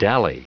Prononciation du mot dally en anglais (fichier audio)
Prononciation du mot : dally